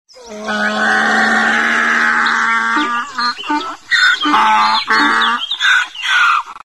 دانلود صدای عرعر خر با صدای بلند از ساعد نیوز با لینک مستقیم و کیفیت بالا
جلوه های صوتی